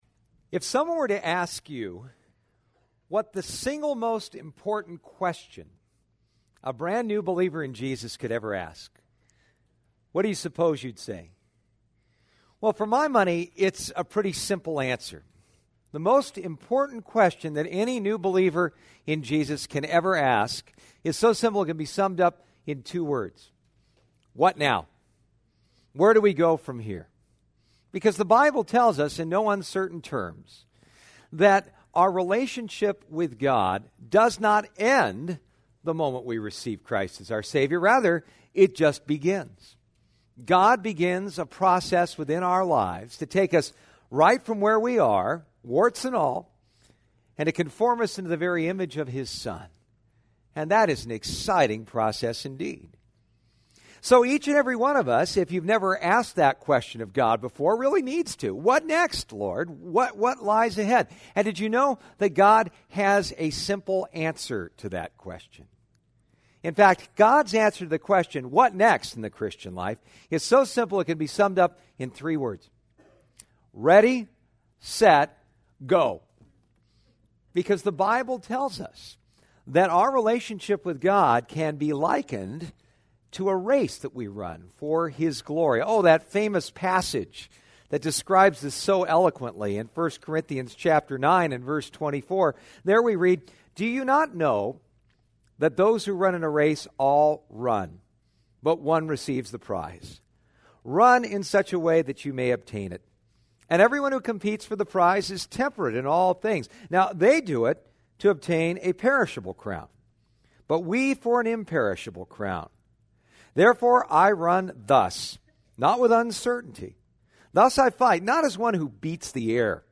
Psalm 16 Service Type: Sunday Morning « Satisfaction Guaranteed The Secret of Spiritual Stability